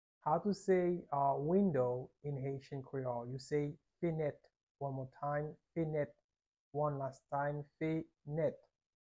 How to say "Window" in Haitian Creole - "Fenèt" pronunciation by a native Haitian Teacher
“Fenèt” Pronunciation in Haitian Creole by a native Haitian can be heard in the audio here or in the video below:
How-to-say-Window-in-Haitian-Creole-Fenet-pronunciation-by-a-native-Haitian-Teacher.mp3